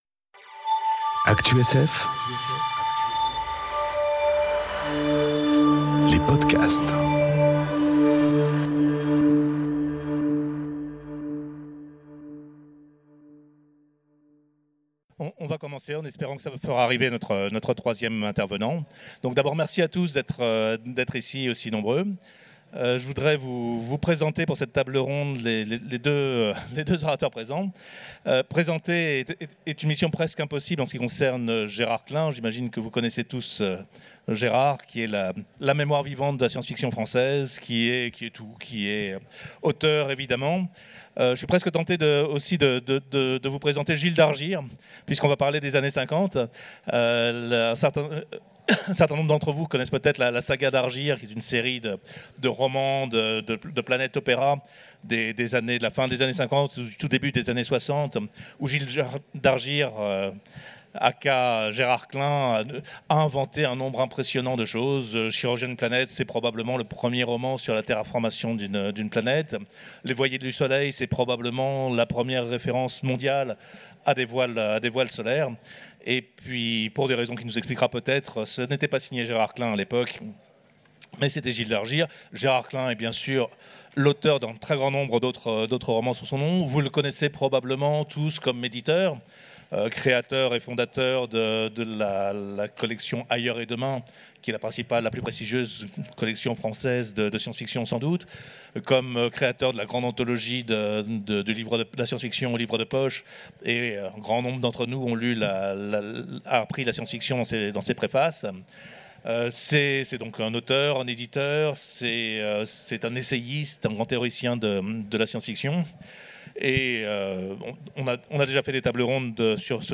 Conférence Nous, les Martiens, ou la faune de l’espace enregistrée aux Utopiales 2018